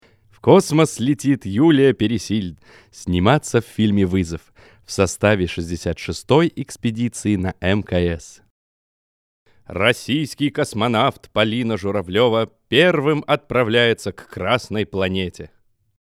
Мужчина 41 год, высшее образование, приятный голос, отличный слух, чтение текста запись вокала.
Shure, Logic